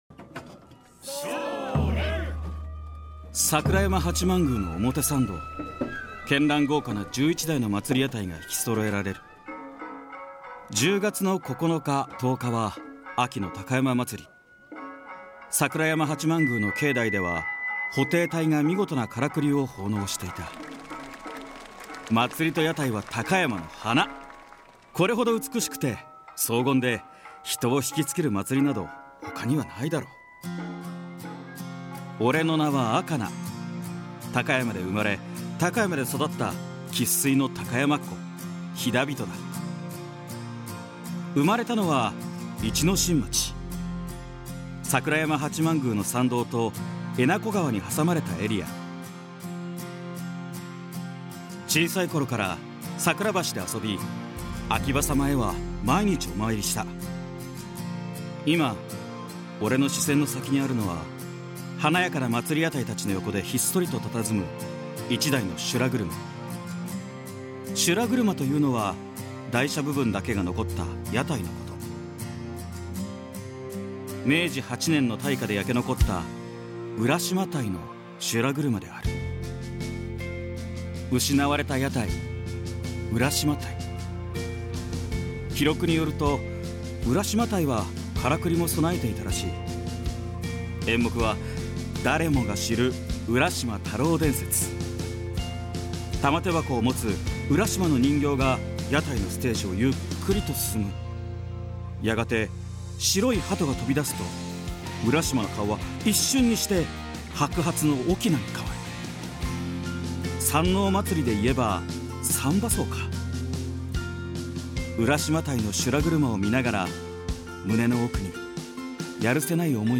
胸熱のボイスドラマ「赤い記憶〜心を映す鏡」を聴きながらじっくり見てくださいね！